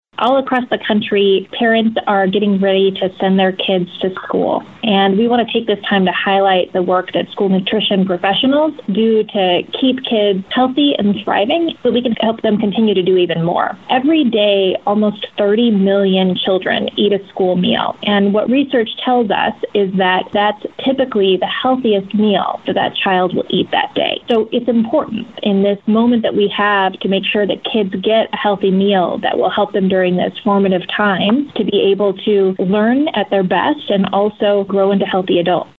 USDA Deputy Secretary Xochitl Torres Small says as the school year gets going, it’s important to make sure students have nutritious meals to eat.